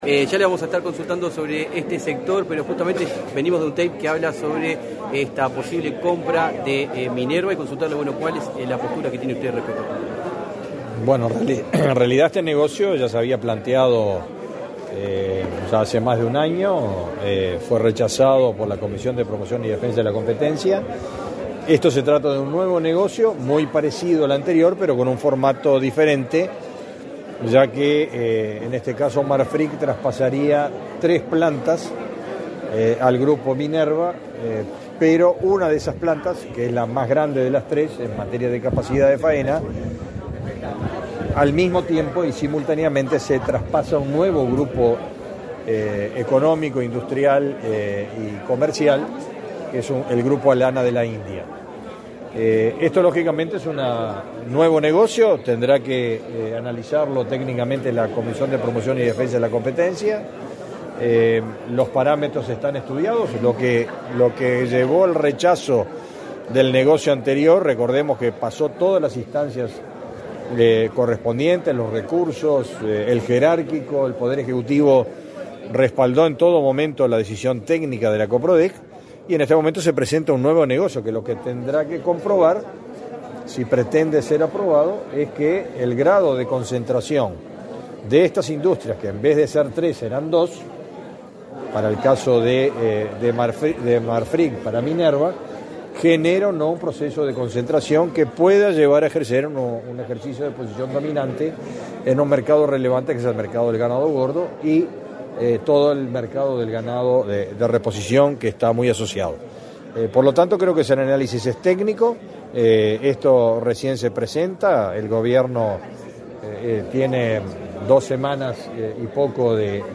Declaraciones del ministro de Ganadería, Agricultura y Pesca, Fernando Mattos
Declaraciones del ministro de Ganadería, Agricultura y Pesca, Fernando Mattos 12/02/2025 Compartir Facebook X Copiar enlace WhatsApp LinkedIn Tras participar en la celebración del 60.° aniversario de la Dirección General Forestal, este 12 de febrero, el ministro de Ganadería, Agricultura y Pesca, Fernando Mattos, realizó declaraciones a la prensa.